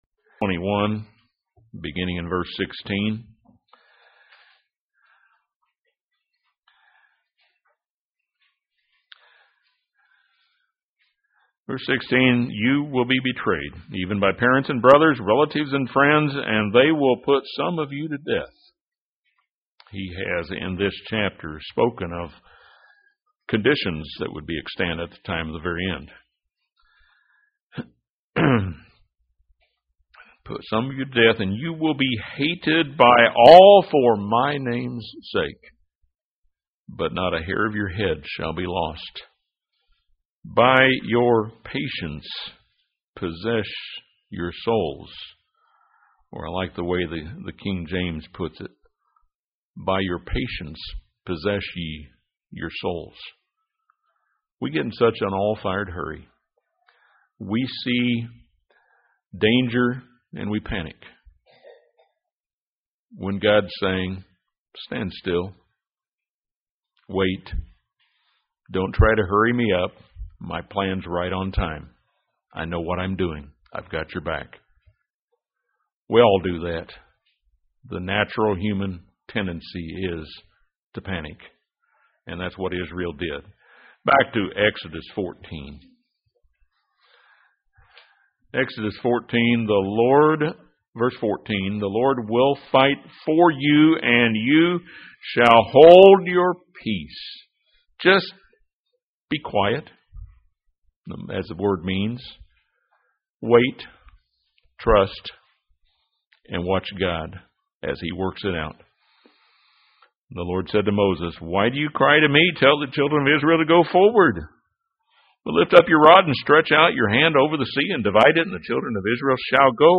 The spring festivals remind us of the baptismal covenant we made with God. This sermon draws four lessons for today's church from Israel's crossing of the Red Sea.